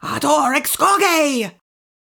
mandrake foundry13data/Data/modules/psfx/library/incantations/older-female/fire-spells/ardor-exsurge